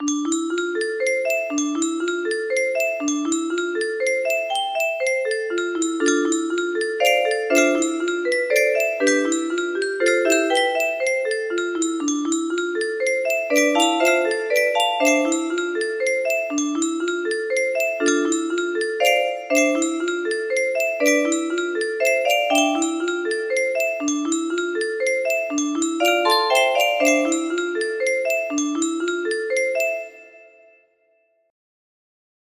orchestrated to be played on a 15-note music box.